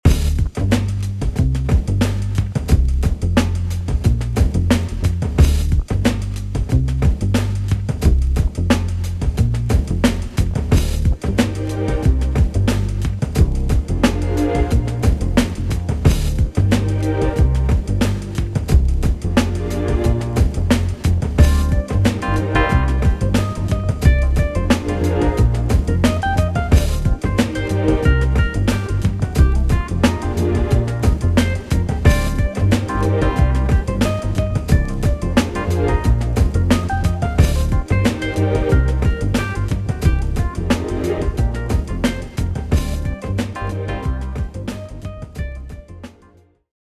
That feeling when it’s nearly 2020, you head to the studio without an objective, and you realize that — apparently! — you’re still thinking at least a little bit about Thievery Corporation.